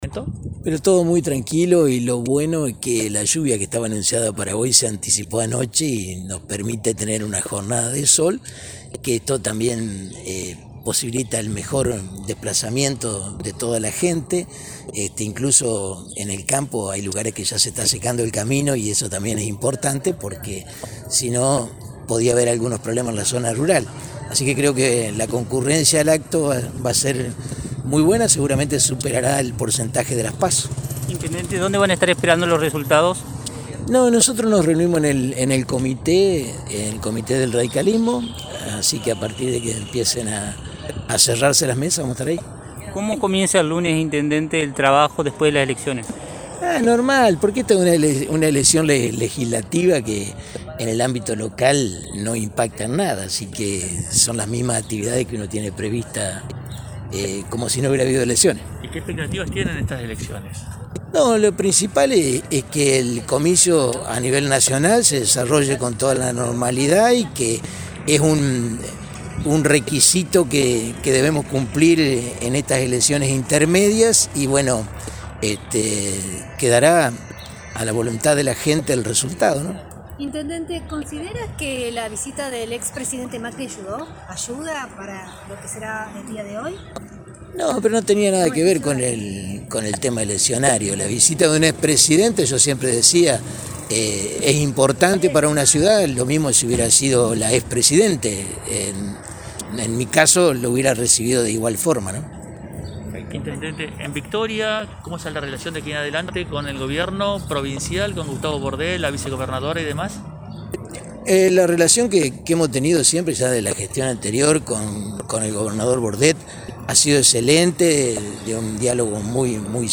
el Intendente, Domingo Maiocco, se mostró tranquilo en declaraciones a periodistas de lt39 y adelantó que esperará el resultado de la elección en la sede del Partido Radical.